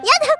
Worms speechbanks
uh-oh.wav